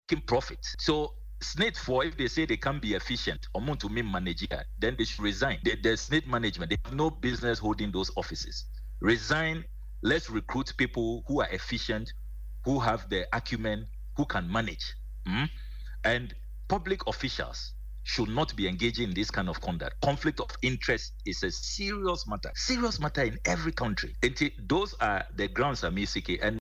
Speaking on Adom FM’s morning show Dwaso Nsem Tuesday, he said SSNIT’s decision to sell more than half of its stake meant that it could no longer manage the facilities, hence his call.